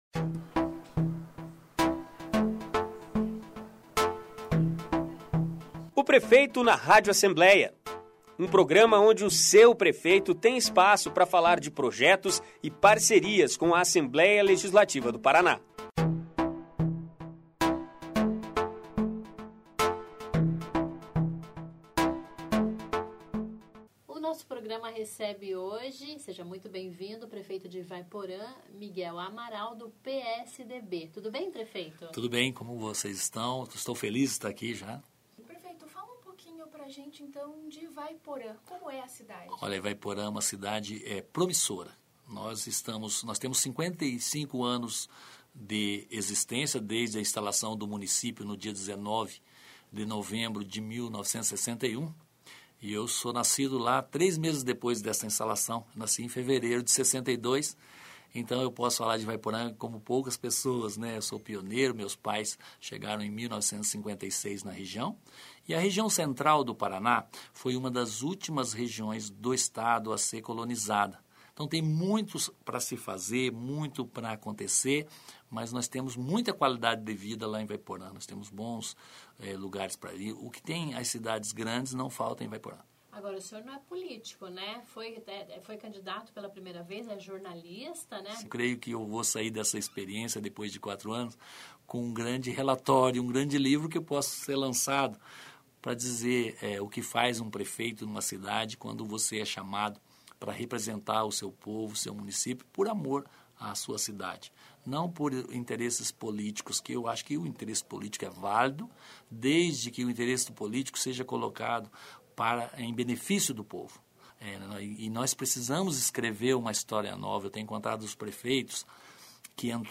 Miguel Amaral, de Ivaiporã, é o convidado do"Prefeito na Rádio Alep". Ouça!